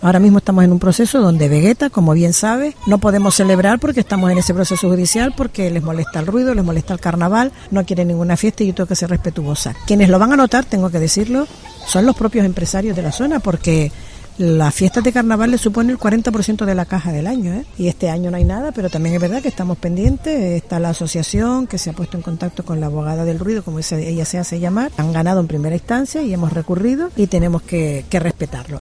Inmaculada Medina, concejala de Carnaval de Las Palmas de Gran Canaria
Cope Gran Canaria ha realizado su programa especial del carnaval en el parque del Estadio Insular donde se ha hecho un repaso de las fiestas y de los eventos que quedan por realizar en la capital.